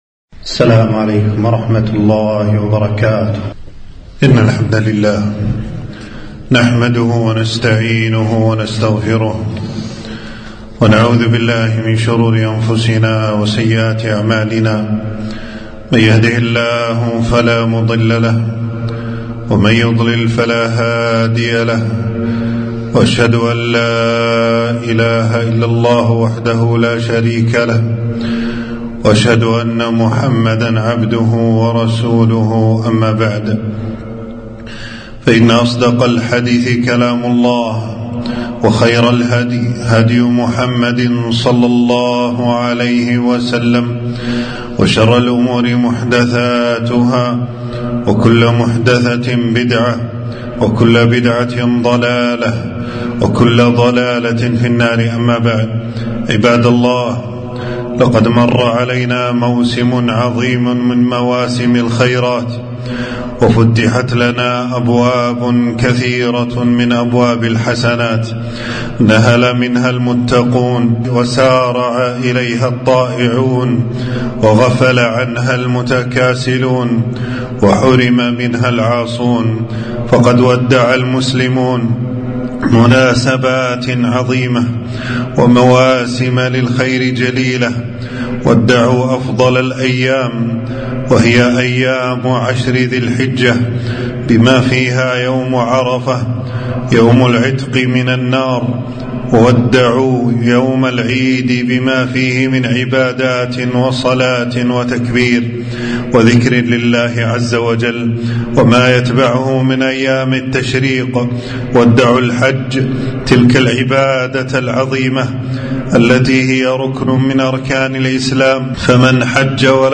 خطبة - الثبات الثبات بعد موسم الطاعات